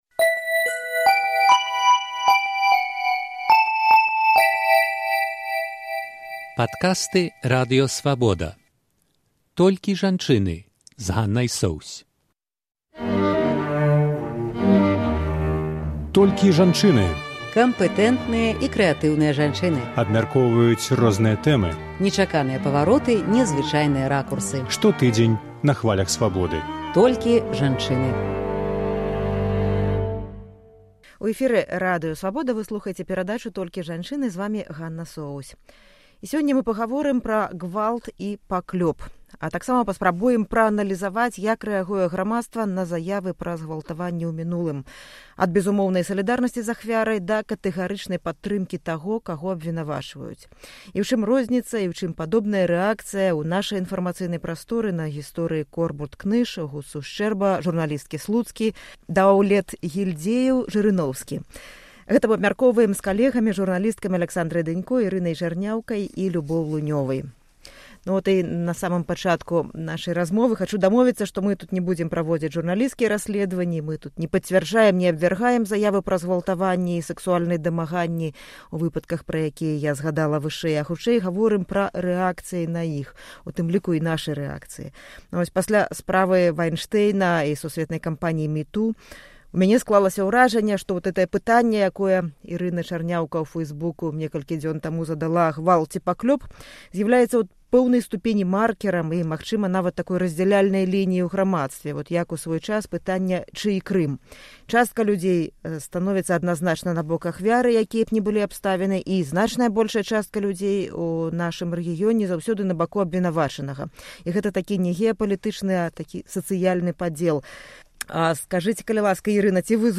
Абмяркоўваюць журналісткі